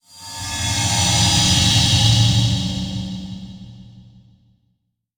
teleport3.wav